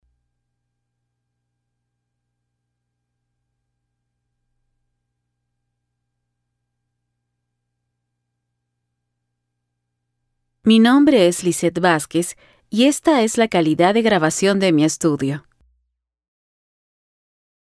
Female
Approachable, Assured, Authoritative, Confident, Conversational, Cool, Corporate, Engaging, Friendly, Natural, Reassuring, Smooth, Soft, Upbeat, Versatile, Warm
Peruvian (native)
Audio equipment: Apollo x6, Soundproof and acoustically treated recording booth